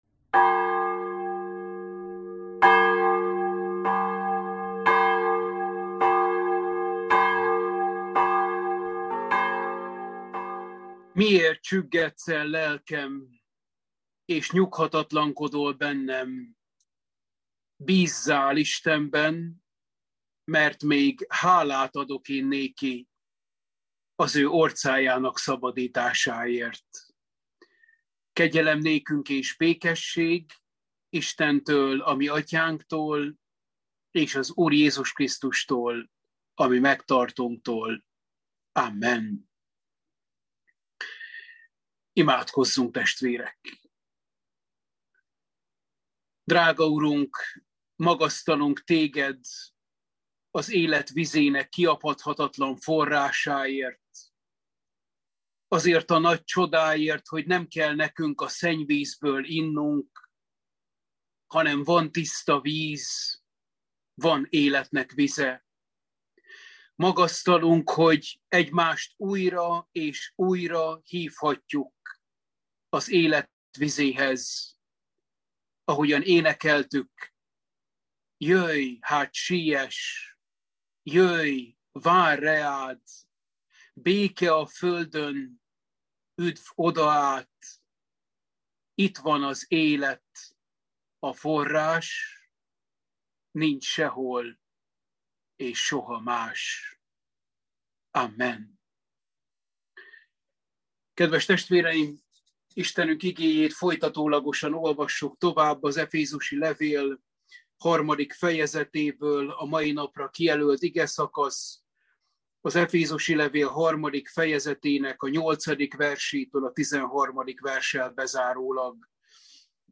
Efézusi levél – Bibliaóra 7